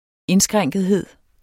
Udtale [ ˈenˌsgʁaŋˀgəðˌheðˀ ]